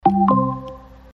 SFX – AMAZON ECHO – NEW NOTIFICATION OR MESSAGE
SFX-AMAZON-ECHO-NEW-NOTIFICATION-OR-MESSAGE.mp3